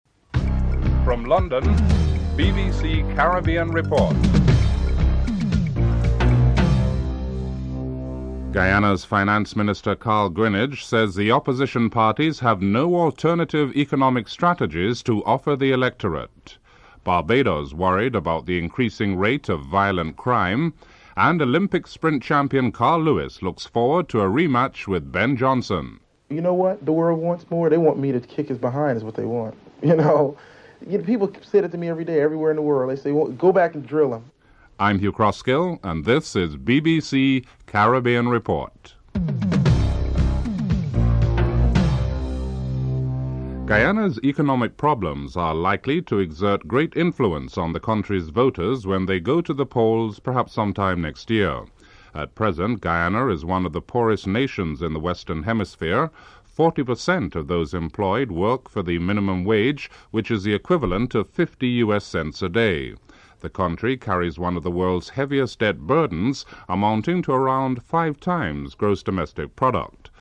anchor
dc.description.tableofcontents1. Headlines (00:00-00:44)en_US